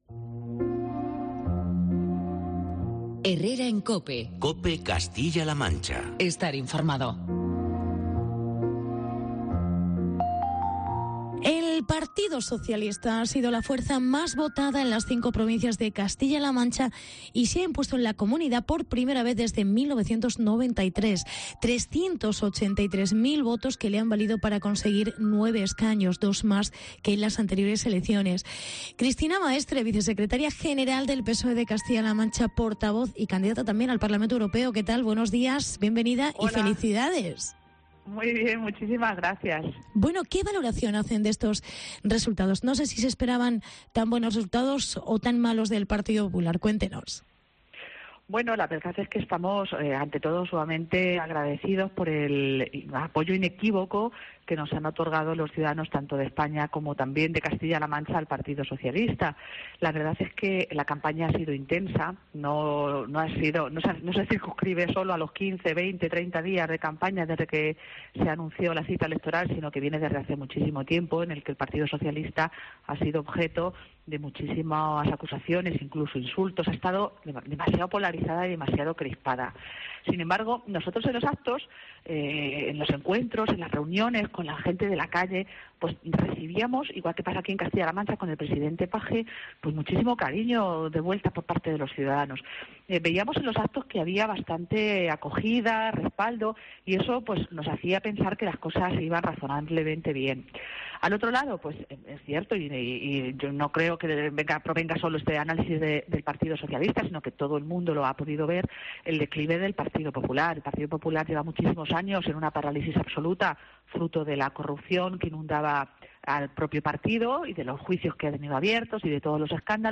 El PSOE se ha impuesto en CLM por primera vez desde 1993. Entrevista con Cristina Maestre